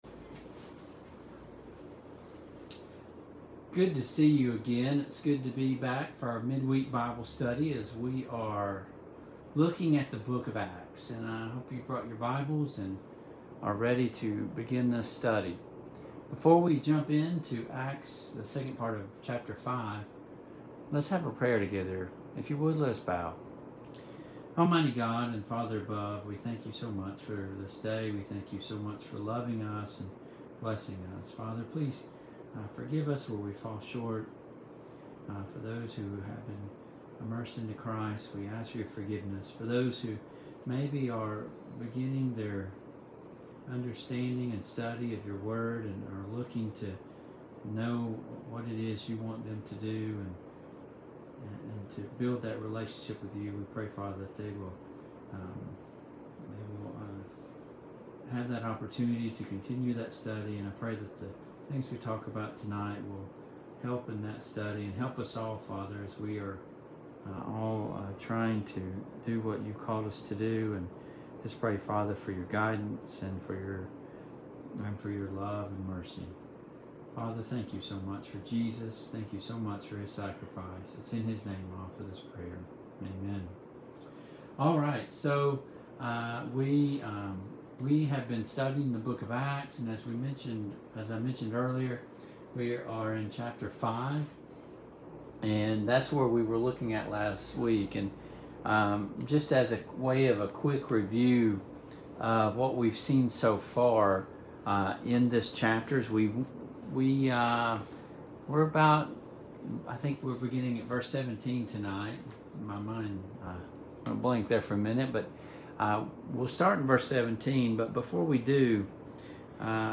Service Type: Online Bible Studies